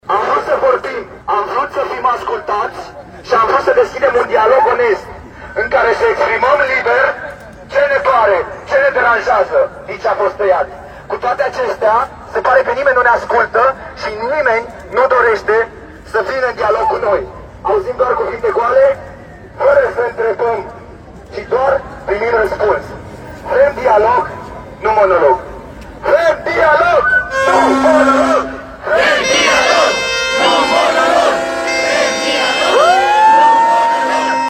02-atmosfera-studenti-protest.mp3